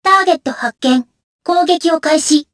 Kara-Vox_Skill1_jp.wav